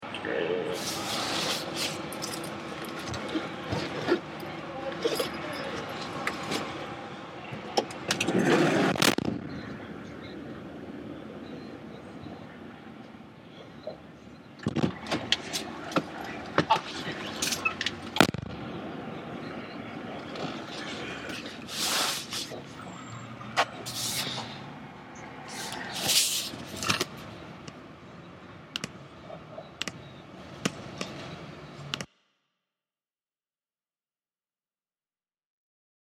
It’s Not A Bird… It’s A PLANE!
Sounds in Clip- A plane flying over head, my hand accidentally scraping against the concrete, and rain
Airport.mp3